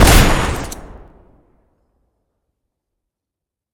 shoot2.ogg